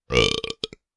描述：对某事感到粗鲁的家伙
标签： 恶心
声道立体声